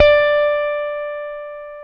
Index of /90_sSampleCDs/AKAI S-Series CD-ROM Sound Library VOL-7/JAZZY GUITAR
JAZZ GT1D4.wav